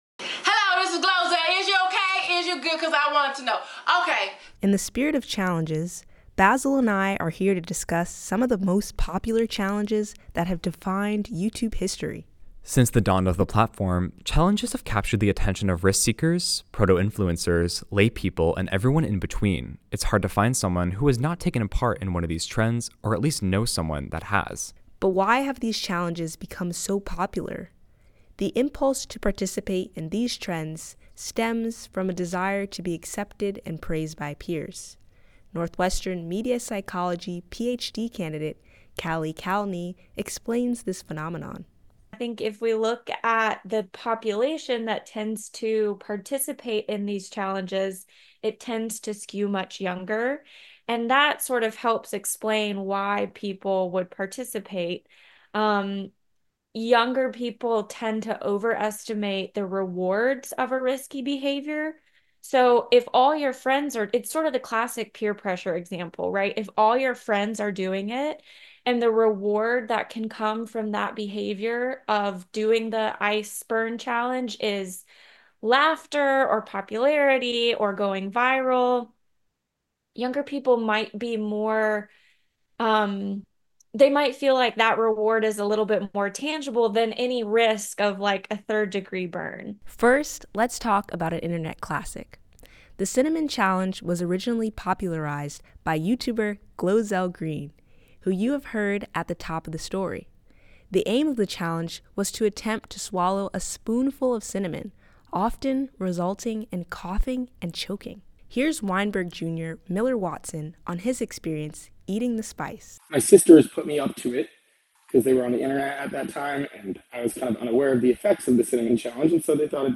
This originally aired as part of our Fall 2024 Special Broadcast: Around the Clock with WNUR News